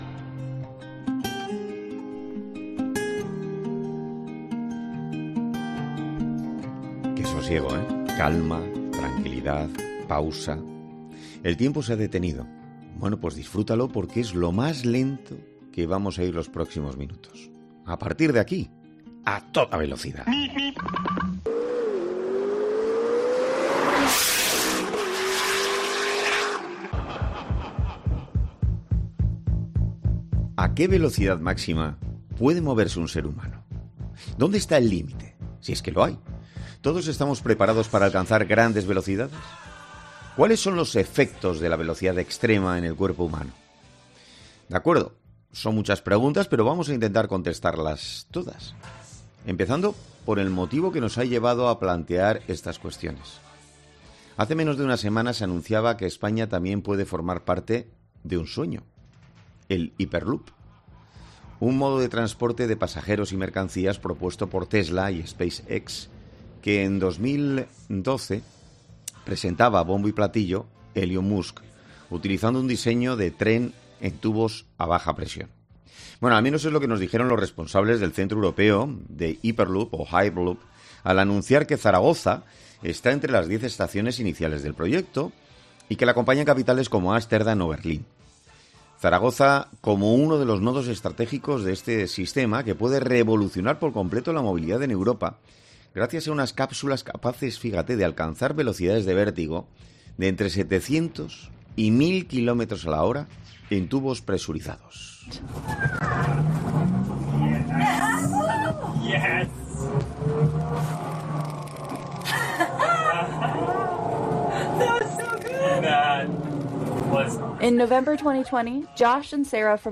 Entrevista a la teniente coronel médico del Cuerpo Militar de Sanidad